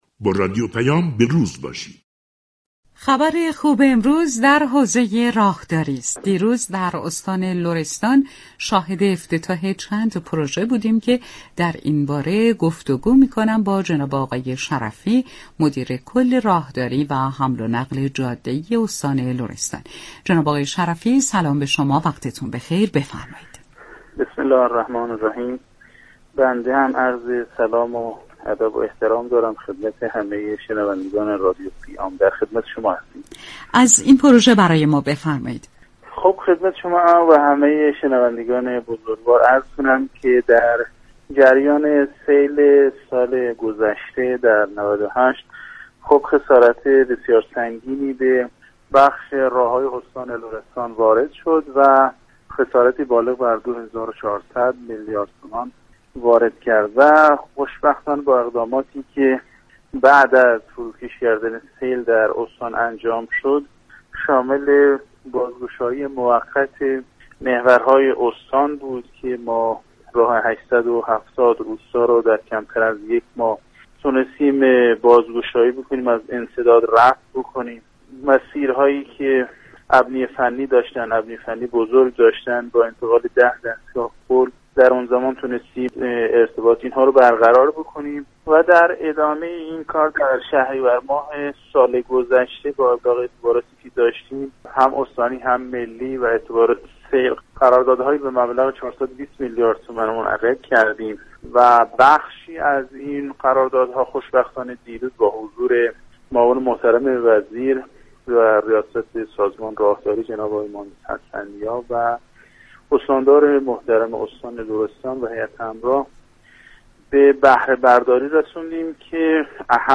شرفی مدیركل راهداری و حمل و نقل جاده‌ای استان لرستان ، در گفتگو با رادیو پیام از افتتاح پروژه های مختلف راهداری در این استان خبر داد .